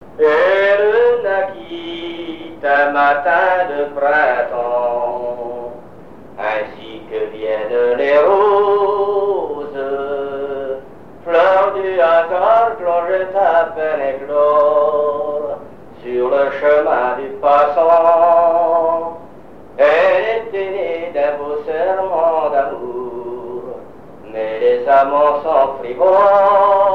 Genre strophique
répertoire de chansons
Pièce musicale inédite